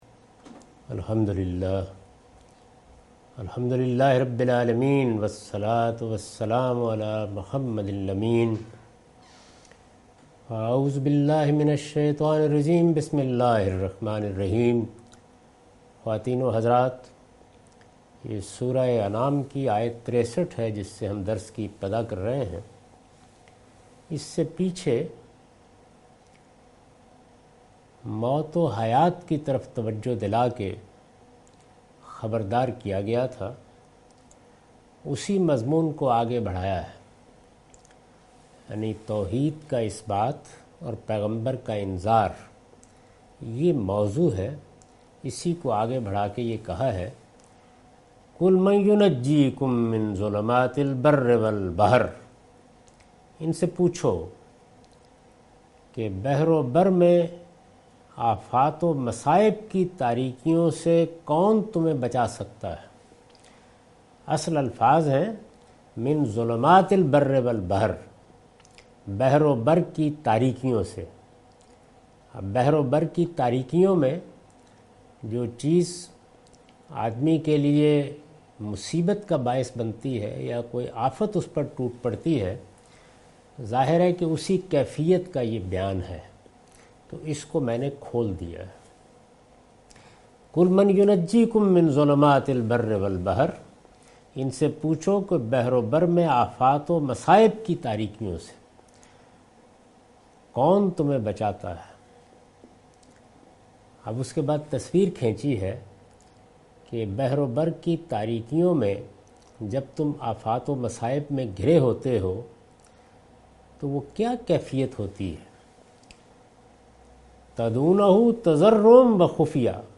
Surah Al-Anam - A lecture of Tafseer-ul-Quran – Al-Bayan by Javed Ahmad Ghamidi. Commentary and explanation of verse 63-69.